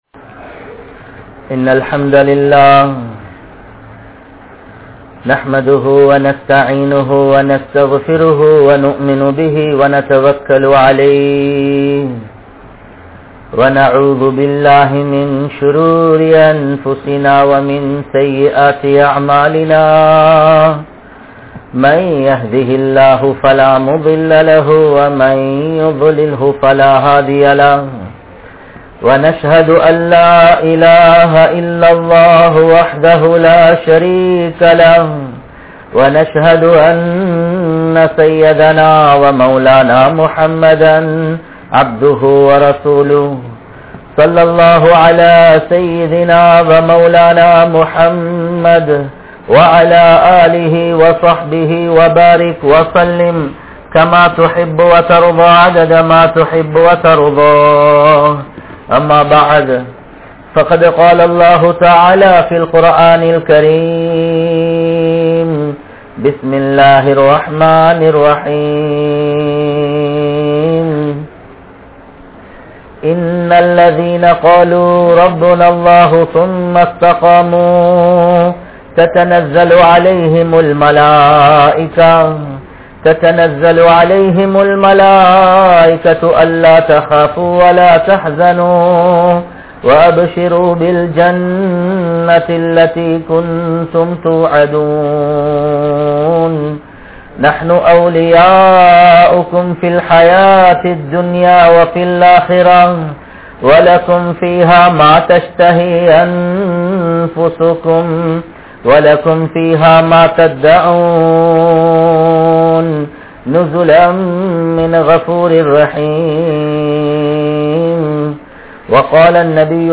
Neengal Mun Maathiriyaana Thaaya(Mother)? | Audio Bayans | All Ceylon Muslim Youth Community | Addalaichenai